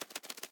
Minecraft Version Minecraft Version snapshot Latest Release | Latest Snapshot snapshot / assets / minecraft / sounds / mob / parrot / fly2.ogg Compare With Compare With Latest Release | Latest Snapshot
fly2.ogg